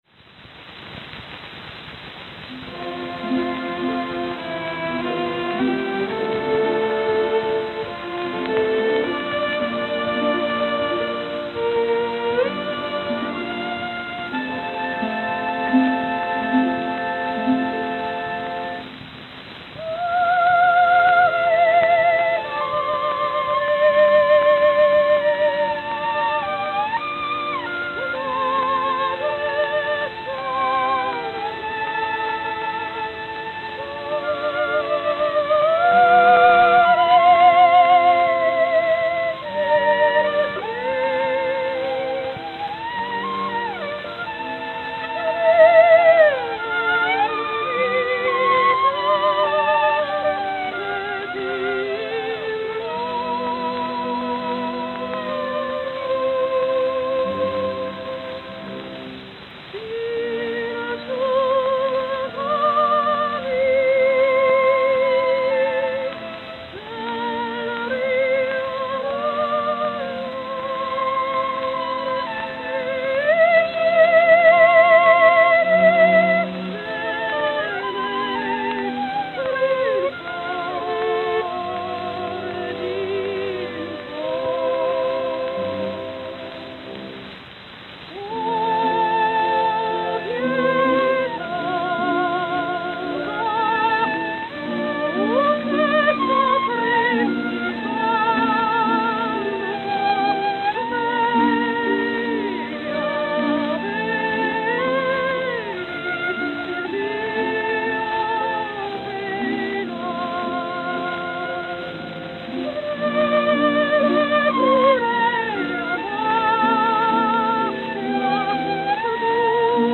It was a prestigious tier featuring High Classical and Operatic selections with a base price of $2.50. It was analogous to Victor's Red Seal Label and Columbia's Symphony Series.
Frieda Hempel